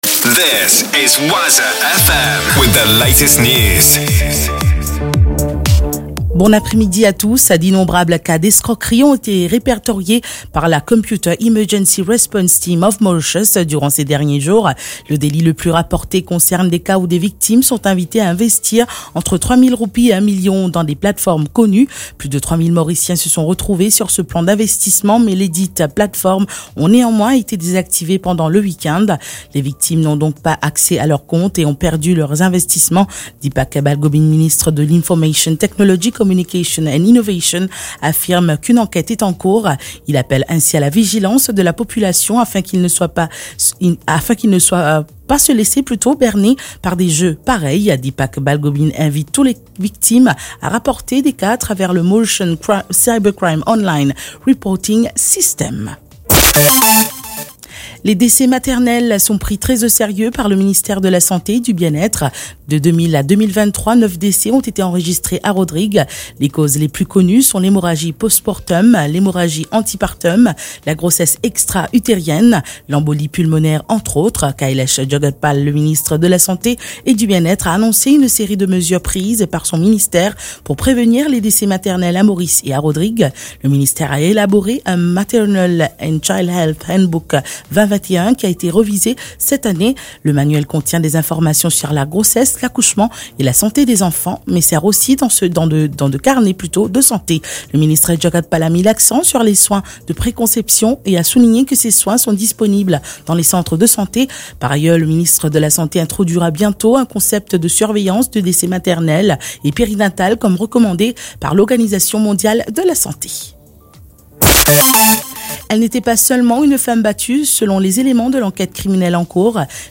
NEWS 15H - 15.11.23